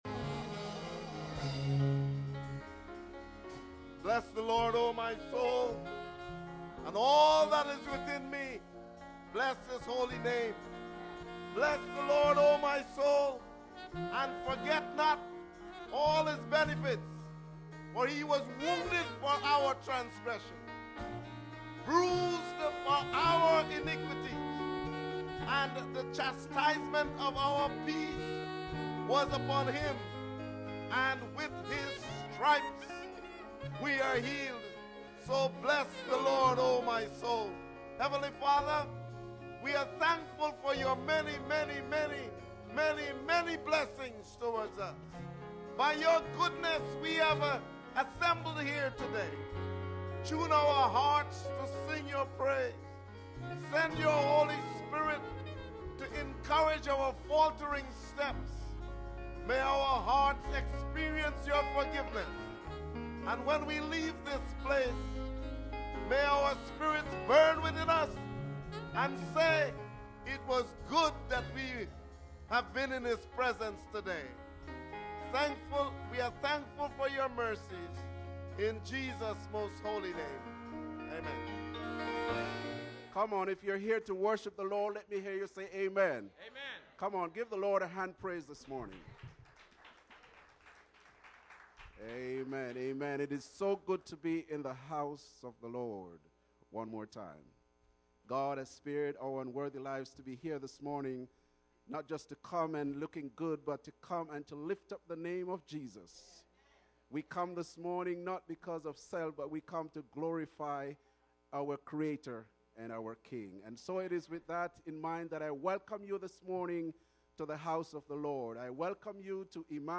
Sermon: It's The Yoke!